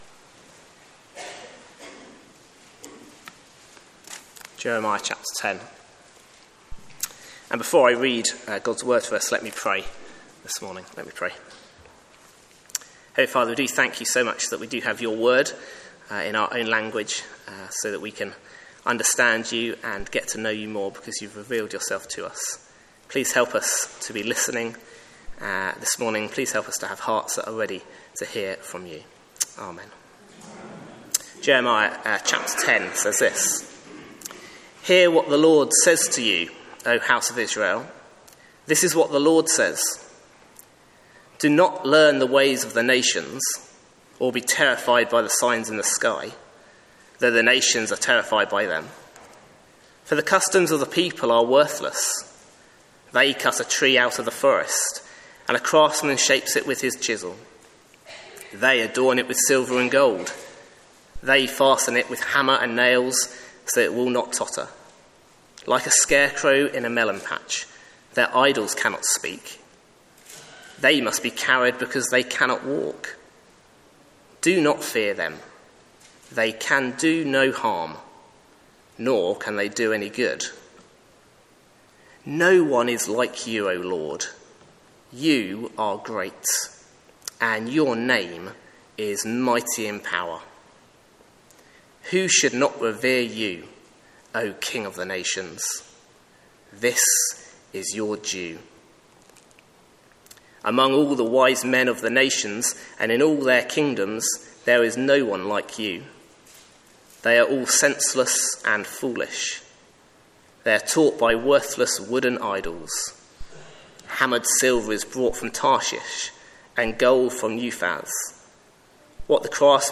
Sermons Archive - Page 85 of 188 - All Saints Preston